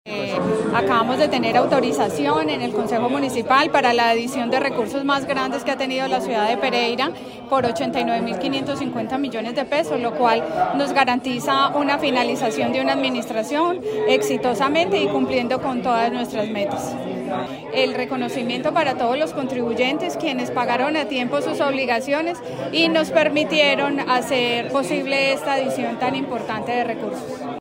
DORA_PATRICIA_OSPINA_SECRETARIA_DE_HACIENDA_PPTO.mp3